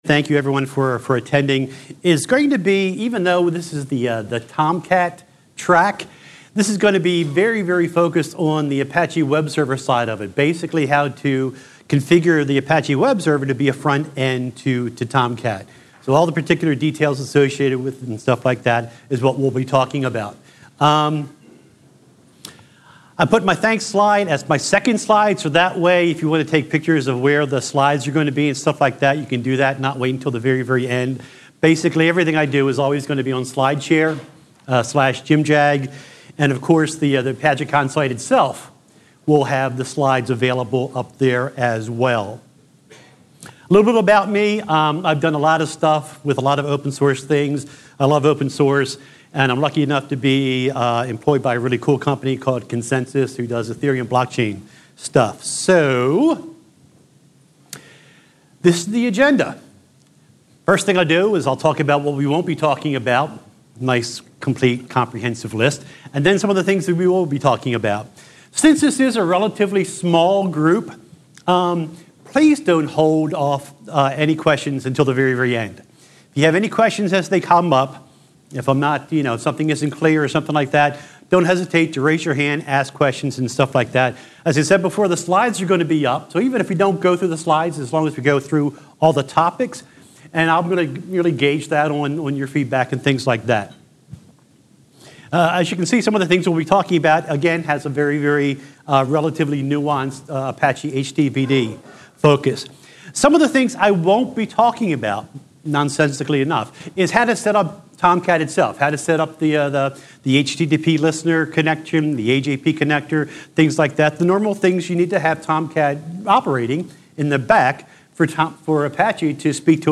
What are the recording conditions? Tags: ApacheCon, apacheconNA2018, Podcasts • Permalink